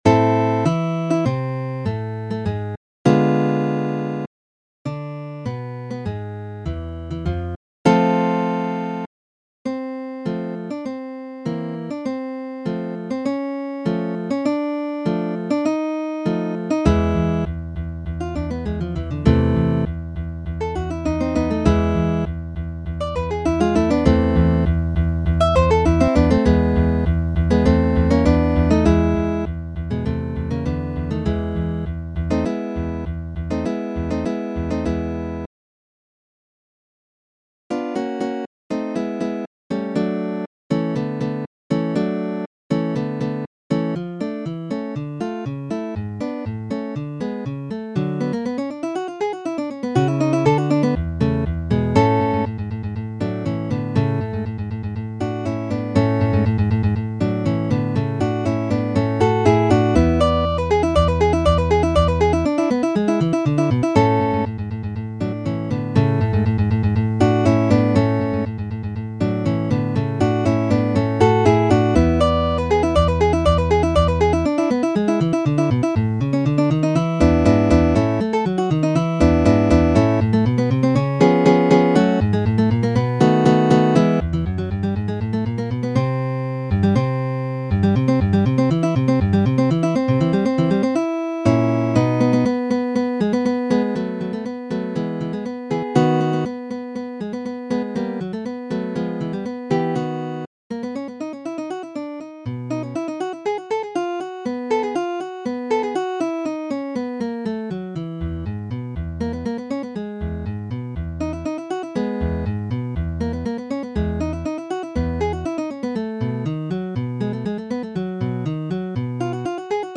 Mi kompilis preskaŭ ĉiujn muzikaĵojn de Fernando Sor (fakte nur tri mankas ankoraŭ) kaj publikigis ilin pere de mia retpaĝo, per MIDI formato pere de programoj Mozart kaj TableEdit.